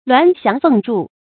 鸞翔鳳翥 注音： ㄌㄨㄢˊ ㄒㄧㄤˊ ㄈㄥˋ ㄓㄨˋ 讀音讀法： 意思解釋： 翔：盤旋而飛；翥：高飛。比喻書法筆勢飛動舒展。